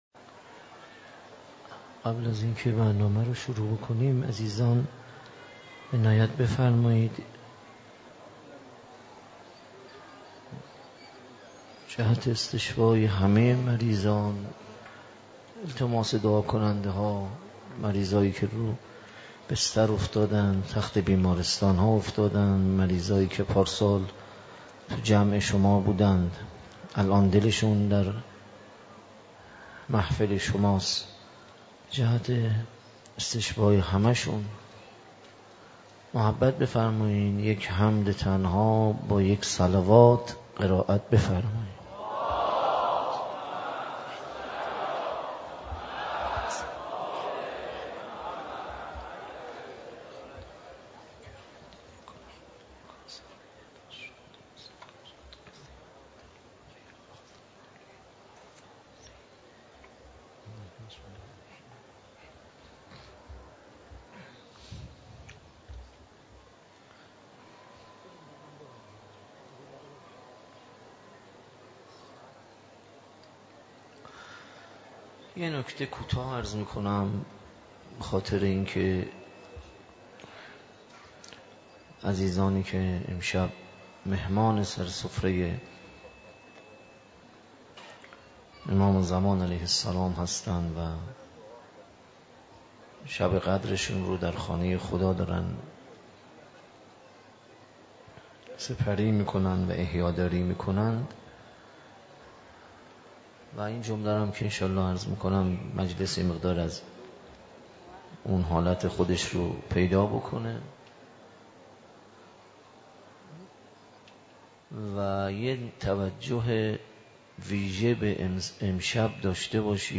در مسجد امام حسین (ع) واقع در میدان امام حسین(ع) برگزار گردید.
شب بیست و یکم ماه مبارک رمضان دعای ابوحمزه